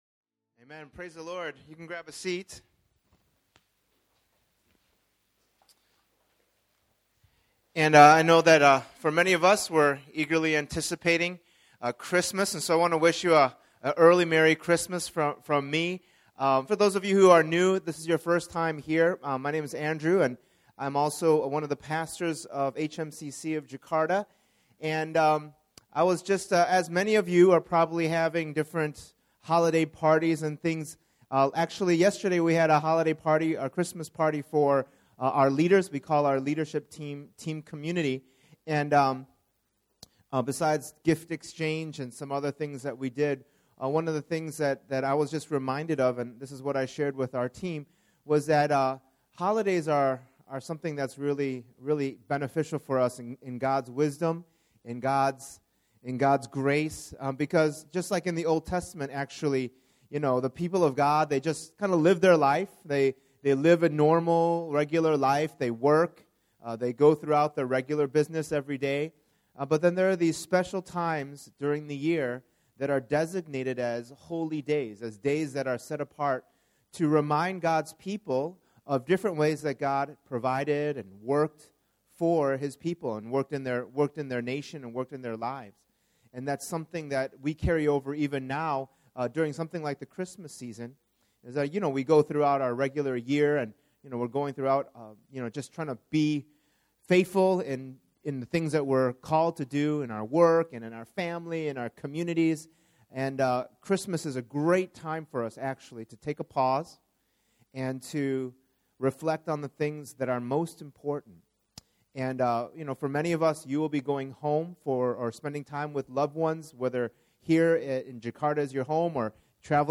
We invite you to our Advent sermon series as we study how Jesus is truly God “Incarnate,” or God in human form.